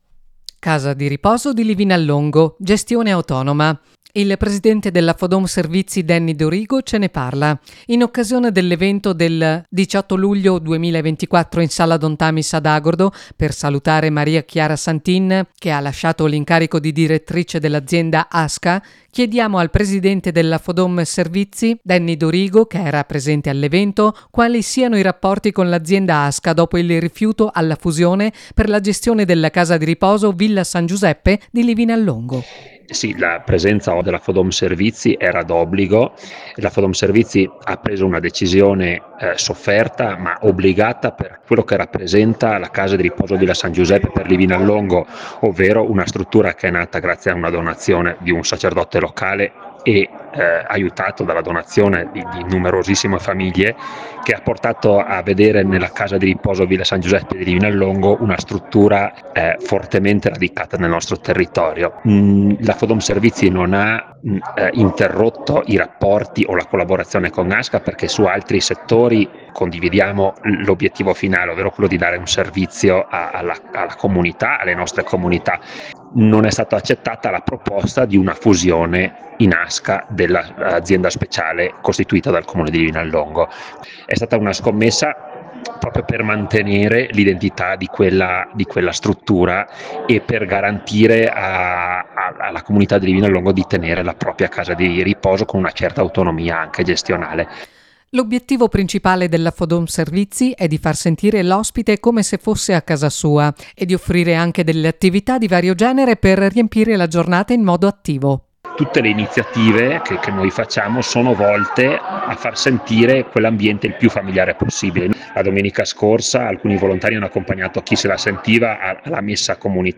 In occasione dell’evento del 18 luglio in sala Don Tamis ad Agordo